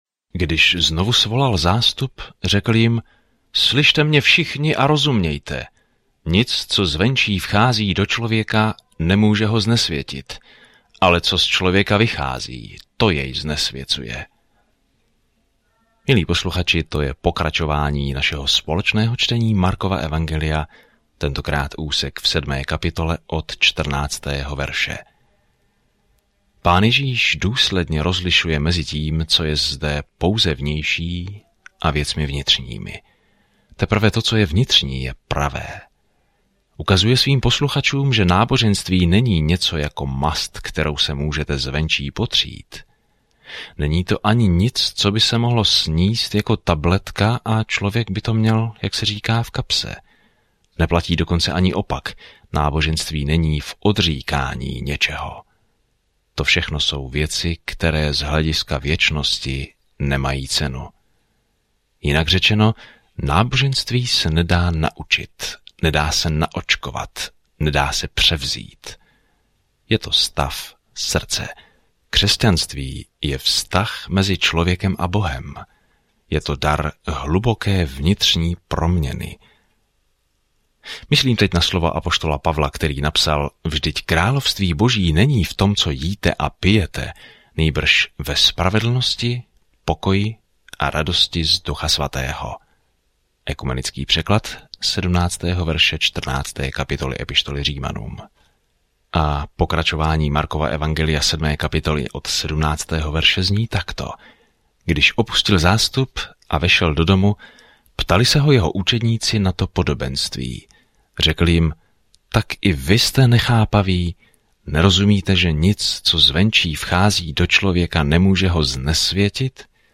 Den 15 Začít tento plán Den 17 O tomto plánu Markovo kratší evangelium popisuje pozemskou službu Ježíše Krista jako trpícího Služebníka a Syna člověka. Denně procházejte Markem a poslouchejte audiostudii a čtěte vybrané verše z Božího slova.